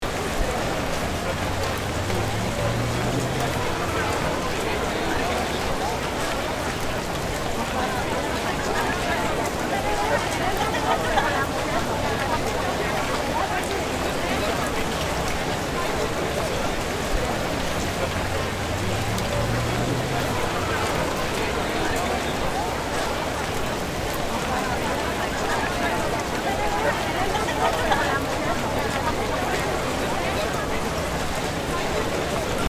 Download Free Rain Sound Effects | Gfx Sounds
Railway-station-rain-day-time-people-chatter-loop.mp3